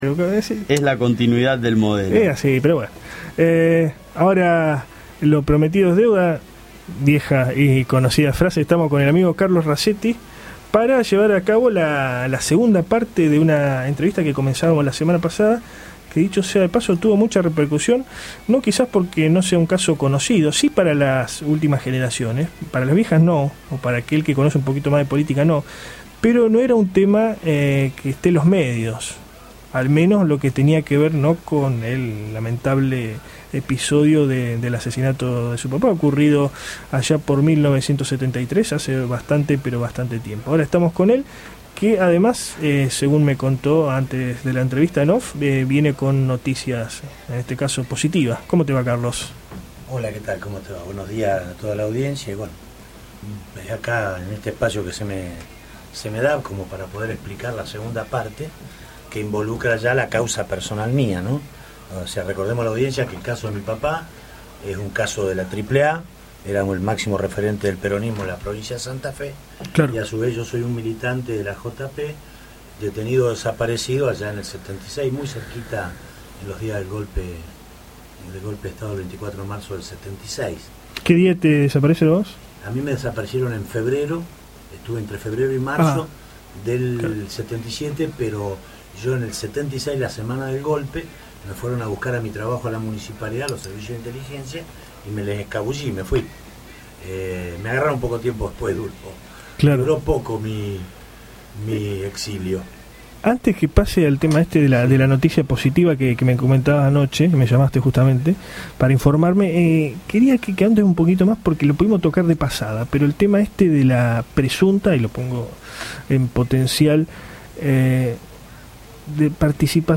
AUDIO ENTREVISTA